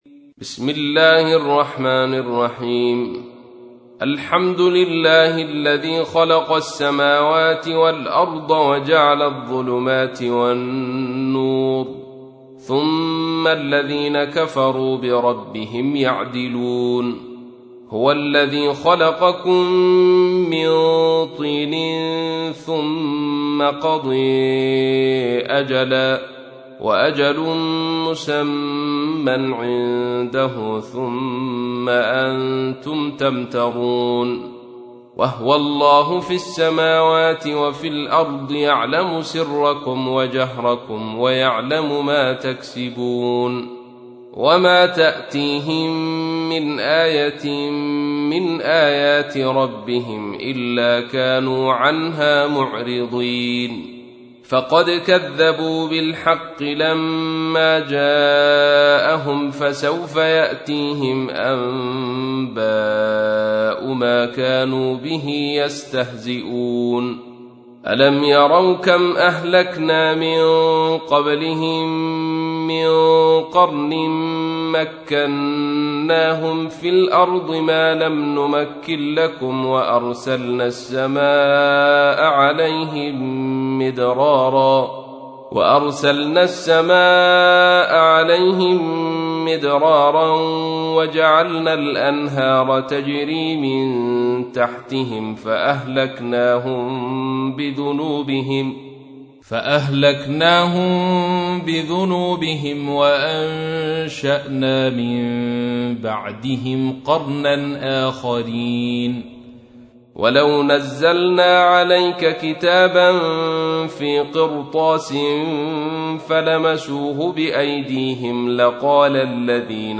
تحميل : 6. سورة الأنعام / القارئ عبد الرشيد صوفي / القرآن الكريم / موقع يا حسين